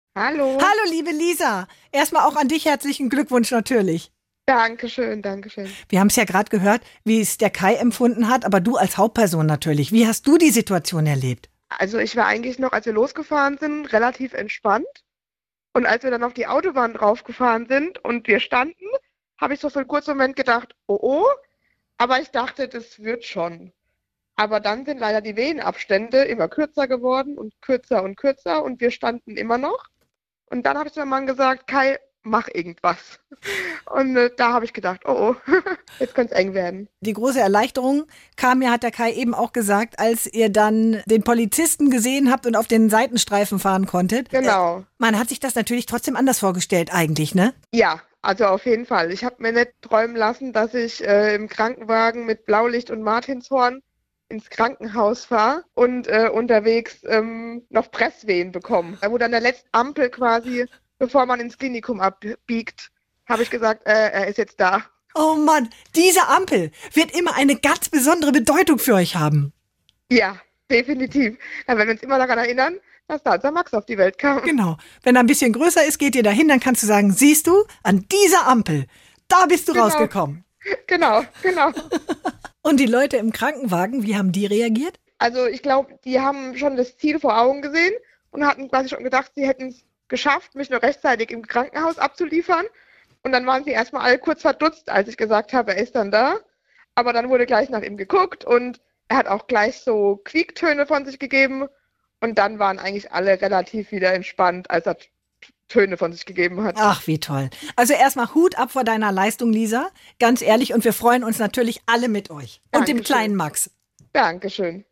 Interview: So war die Geburt im Rettungswagen